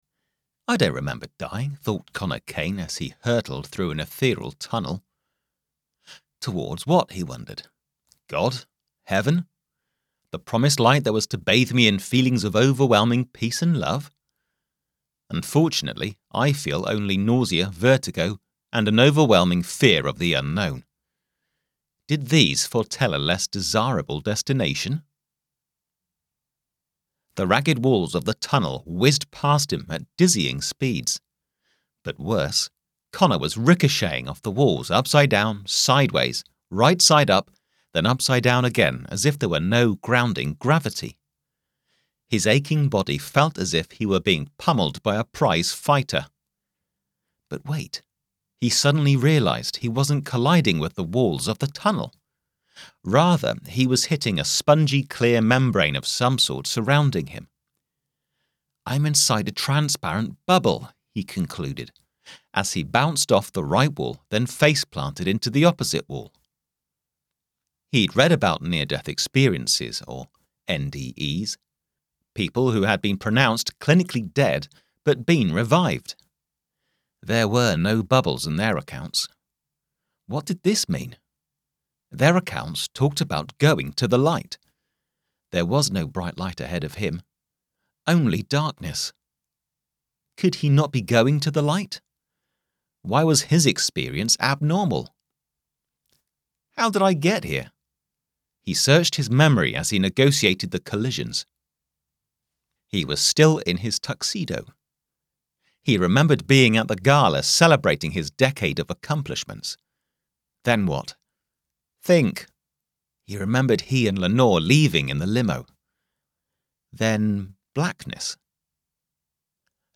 Preview the suspense of the first 5 minutes of The Encore AudioBook: